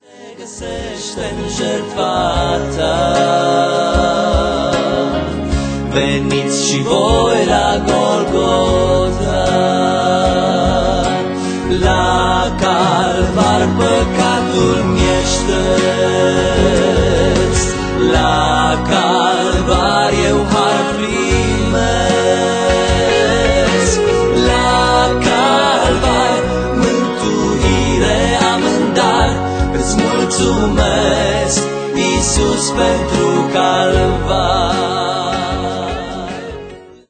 aranjamentul instrumental